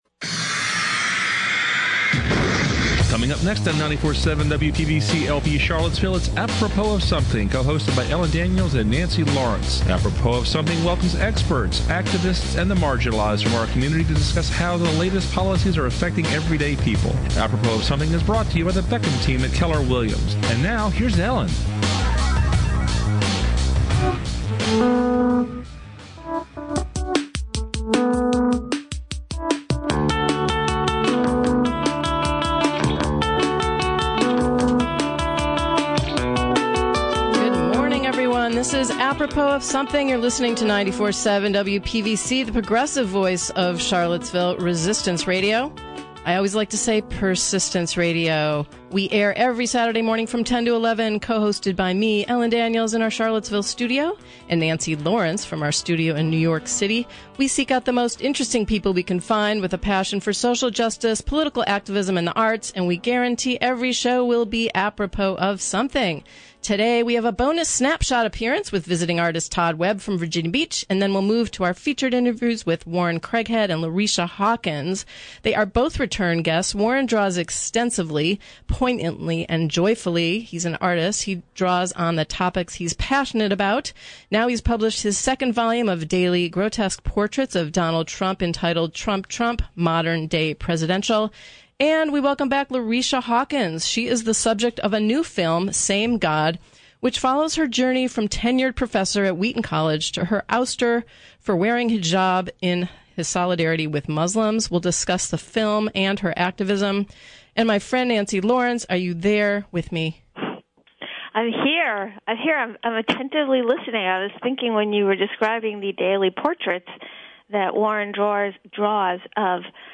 Apropos Of Something seeks out guests who are passionate about the arts, politics and society at-large.